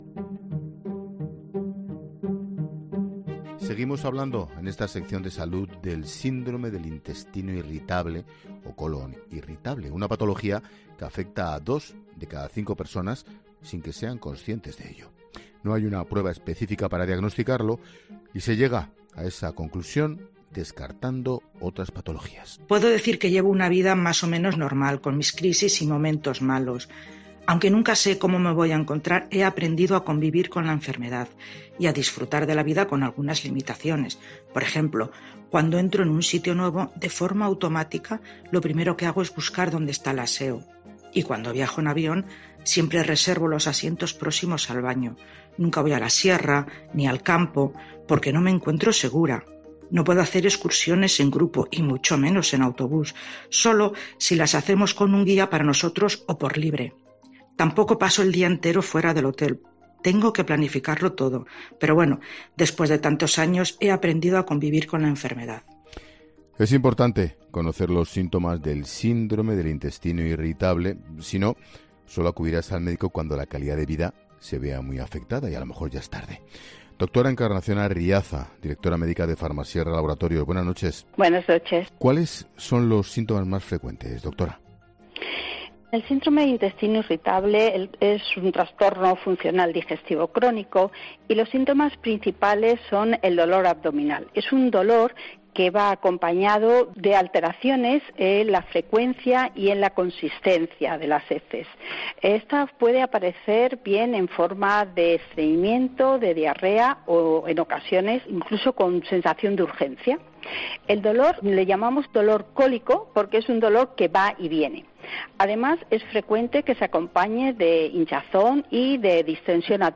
Nos atiende para conocer un poquito mejor este trastorno.